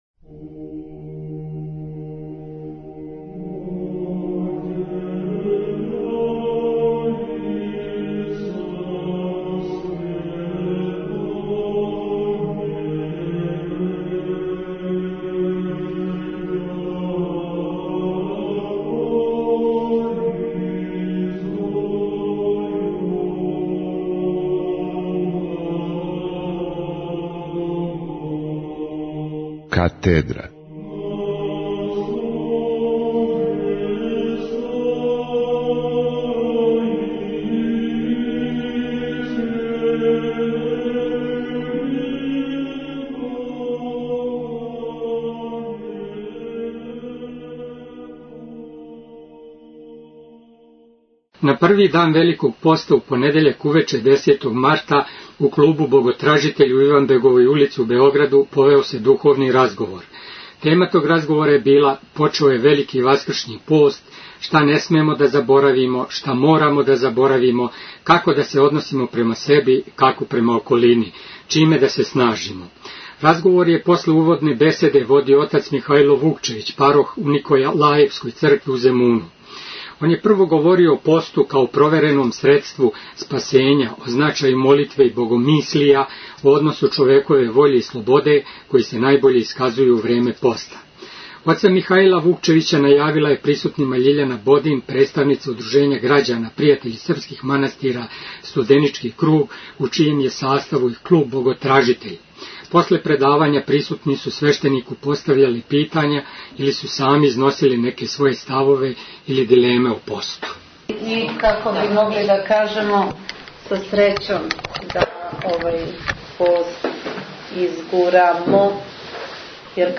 Предавање
одржано у клубу Боготражитељ у Београду.